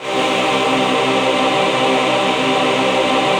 DM PAD4-12.wav